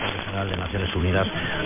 描述：西班牙语广播新闻报道的片段。
Tag: 声音 西班牙语 收音机 特温特 大学 AM 新闻阅读器